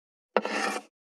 576まな板の上,包丁,ナイフ,調理音,料理,
効果音